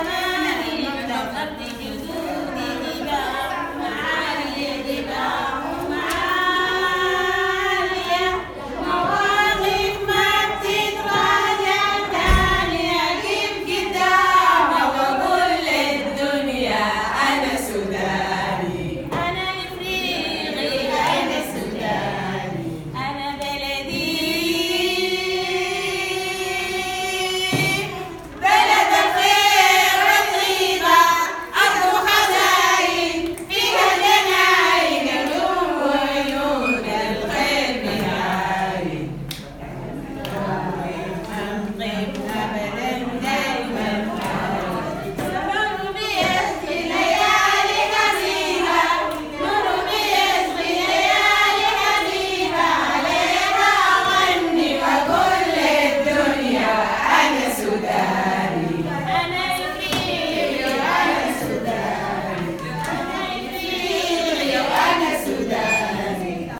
The work of the training sessions is always punctuated by song. Just before starting on role plays to stimulate discussion on small income projects and waste clearance initiatives, some of our literacy workers broke into this wonderful song: I am Sudanese, I am African –